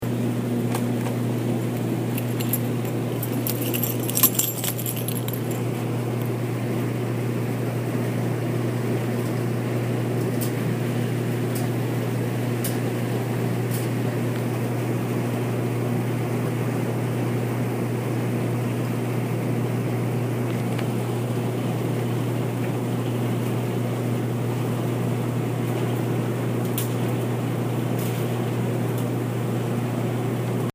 Field Recording #6
Location: Constitution Hall Laundry Room
Sounds Heard: Keys jingling, dryers and washers going, clothes spinning in the dryers.
Field-recording6.mp3